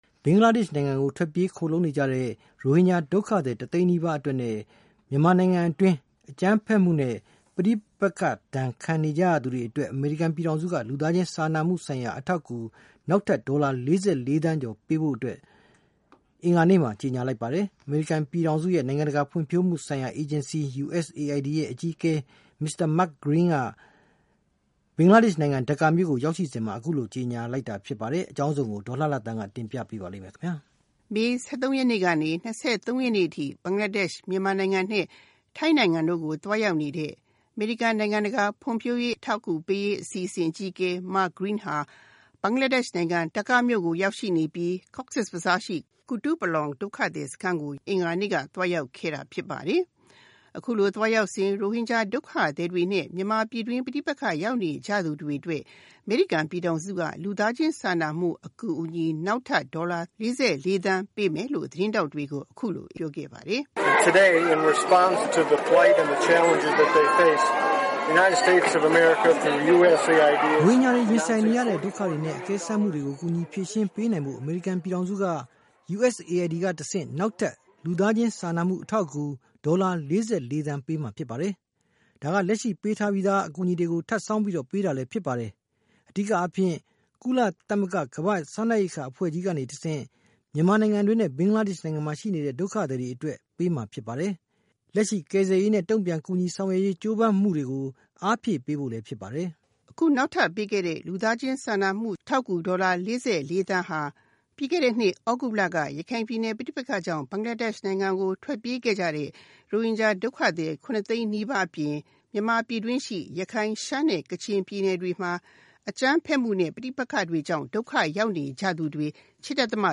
မေ ၁၃ ကနေ ၂၃ ရက်နေ့အထိ ဘင်္ဂလားဒေ့ရှ်၊ မြန်မာ ၊ ထိုင်းနိုင်ငံတို့ကို သွားရောက်နေတဲ့ အမေရိကန် နိုင်ငံတကာဖွံ့ဖြိုးရေးအထောက်အကူပေးရေး အစီအစဉ် အကြီးအကဲ Mark Green ဟာ ဘင်္ဂလားဒေ့ရှ်နိုင်ငံ ဒါကာမြို့ရောက်ရှိနေပြီး Cox's bazar ရှိ ကူတုပလောင် ဒုက္ခသည်စခန်းကို အင်္ဂါနေ့က သွားရောက်ခဲ့တာဖြစ်ပါတယ်။ အခုလို သွားရောက်စဉ် ရိုဟင်ဂျာ ဒုက္ခသည်တွေနဲ့ မြန်မာပဋိပက္ခရောက်နေသူတွေအတွက် အမေရိကန်ပြည်ထောင်စုက လူသားချင်းစာနာမှုဆိုင်ရာအထောက်အကူ နောက်ထပ် ဒေါ်လာ ၄၄ သန်းပေးမယ်လို့ သတင်းထောက်တွေကို အခုလိုပြောပါတယ်။
အခု နောက်ထပ်ပေးမယ့် လူသားချင်းစာနာမှုဆိုင်ရာအထောက်အကူ ဒေါ်လာ ၄၄ သန်းဟာ ပြီးခဲ့တဲ့နှစ် သြဂုတ်လက ရခိုင်ပြည်နယ် ပဋိပက္ခကြောင့် ဘင်္ဂလားဒေ့ရှ်ဘက်ကို ထွက်ပြေးခဲ့ရတဲ့ ဒုက္ခသည် ၇ သိန်းနီးပါးအပြင် ၊ မြန်မာပြည်တွင်းရှိ ရခိုင်၊ ရှမ်းနဲ့ ကချင်ပြည်နယ်တွေမှာ အကြမ်းဖက်မှုနဲ့ ပဋိပက္ခတွေကြောင့် ဒုက္ခရောက်နေကြသူတွေ ၈.၃ သန်းတို့အတွက် အရေးပေါ်ကူညီရေးပစ္စည်းတွေပေးမှာဖြစ်တယ်လို့ အမေရိကန် နိုင်ငံခြားရေး ဝန်ကြီးဌာန ပြောခွင့်ရ Heather Nauert က အင်္ဂါနေ့ ပုံမှန်သတင်းစာရှင်းလင်းပွဲမှာ အခုလိုပြောပါတယ်။